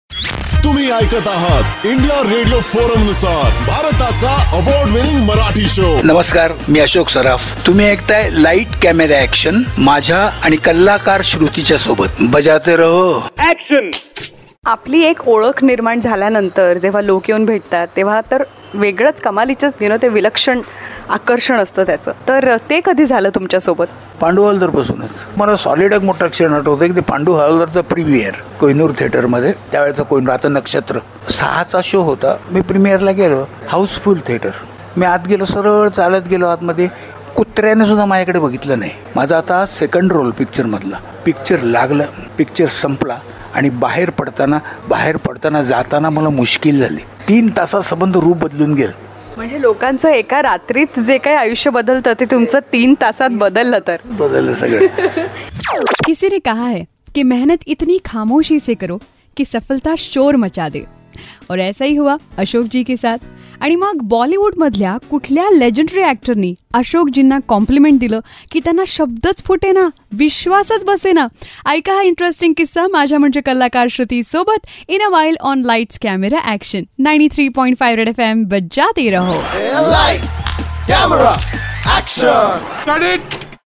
VETRAN ACTOR ASHOK SARAF TALKS ABOUT HIS MOVIE PANDU HAWALDAR